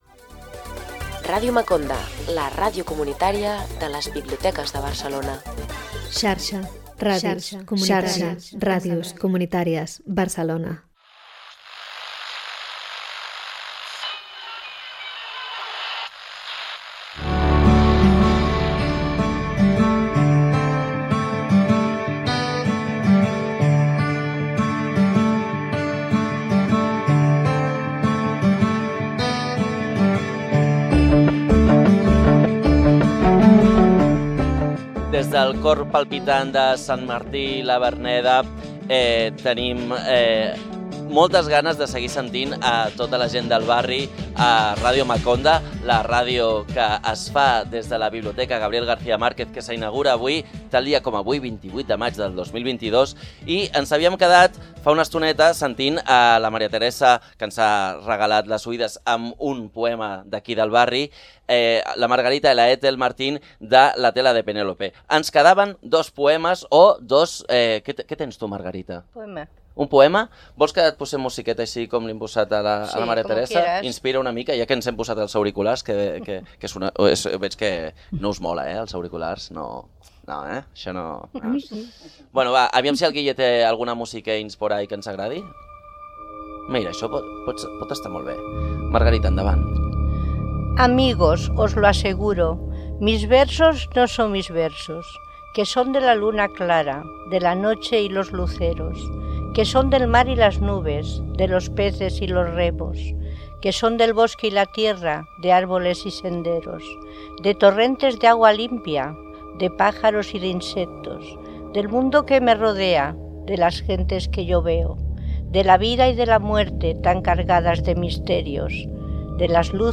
Entrevista al dibuixant Francisco Ibáñez, veí del barri, creador de "Mortadelo y Filemón"